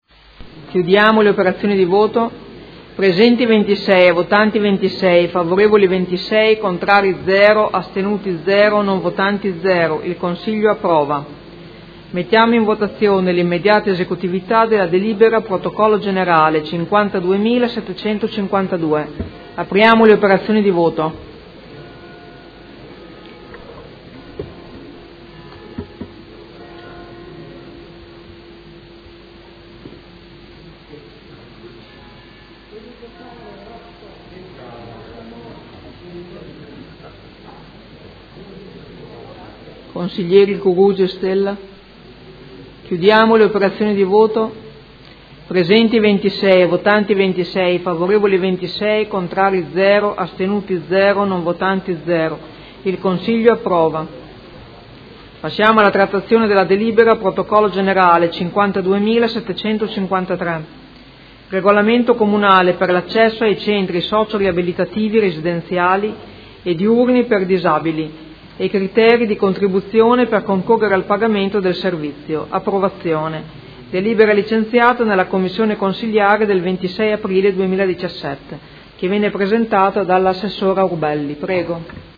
Presidentessa — Sito Audio Consiglio Comunale
Seduta dell'11/05/2017 Mette ai voti. Regolamento comunale per l’accesso alle Case residenza e ai Centri diurni per anziani e criteri di contribuzione per concorrere al pagamento del servizio – Approvazione.